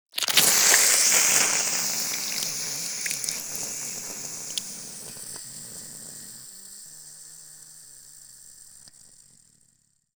Acid_Near_02.ogg